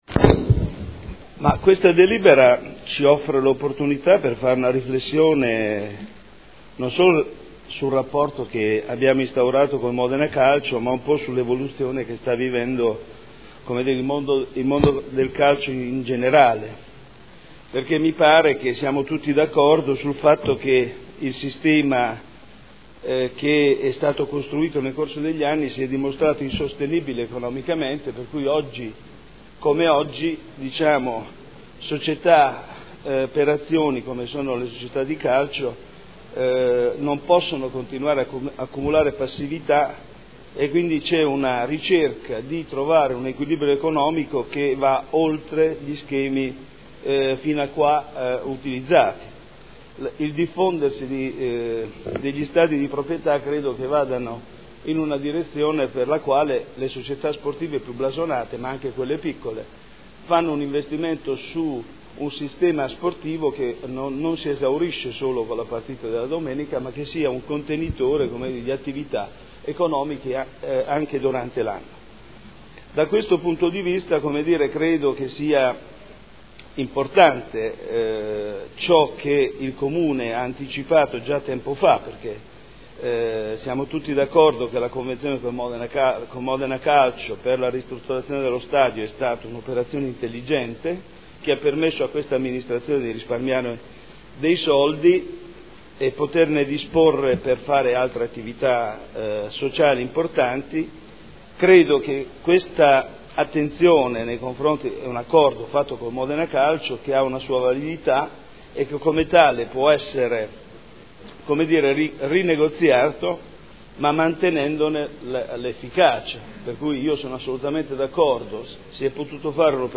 Seduta del 7 aprile. Proposta di deliberazione: Convenzione per la gestione dello stadio comunale Alberto Braglia al Modena FC SpA – Prolungamento durata. Dibattito